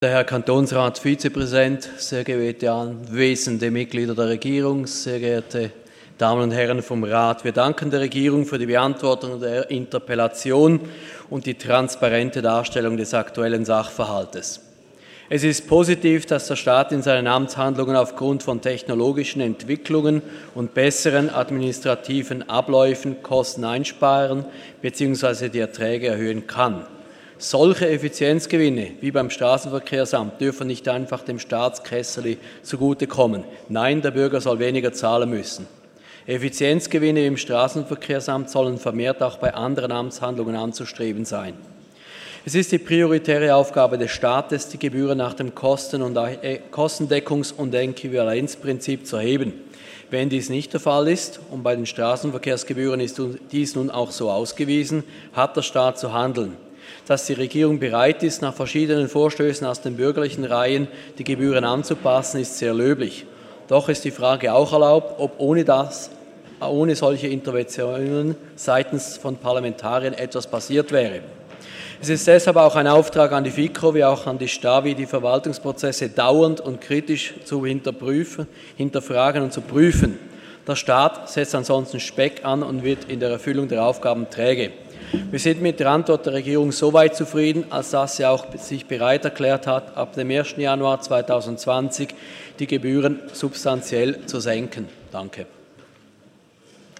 16.9.2019Wortmeldung
Session des Kantonsrates vom 16. bis 18. September 2019